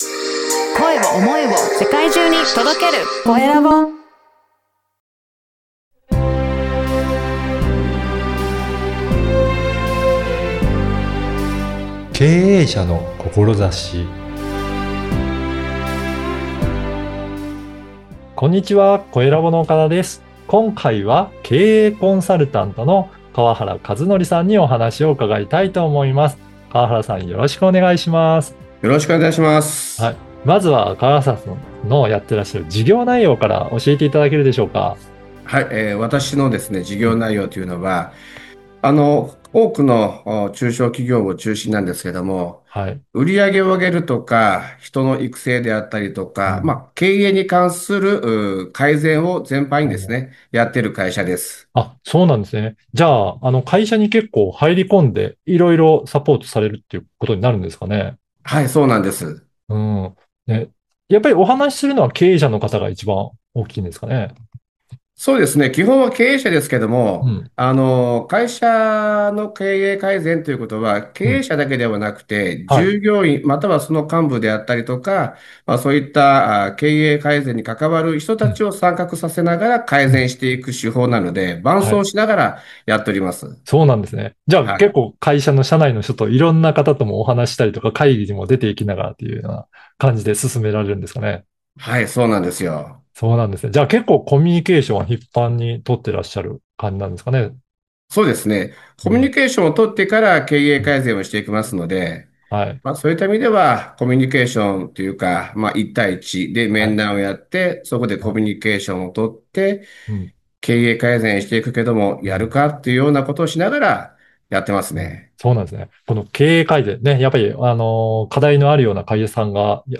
20年以上の経験から語られる、経営改善の本質について語っていただきました。 【今回のゲスト】